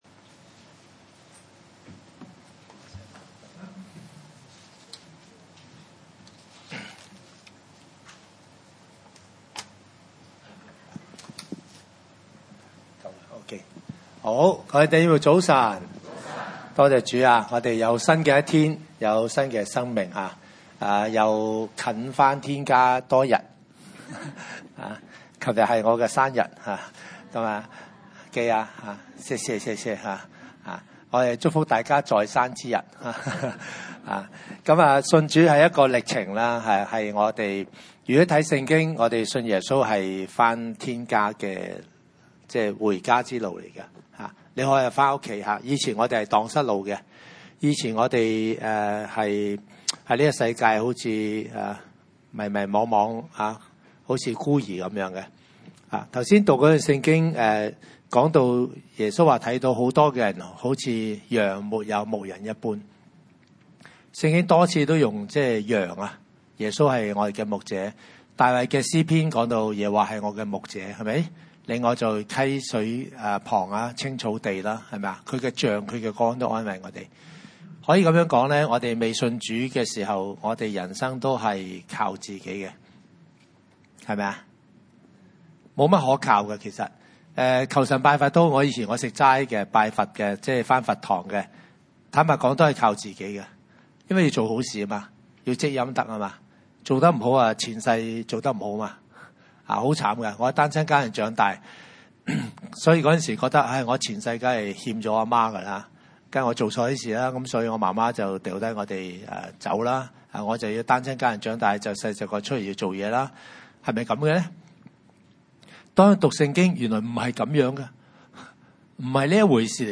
經文: 馬太福音9：35 – 10：1 崇拜類別: 主日午堂崇拜 35.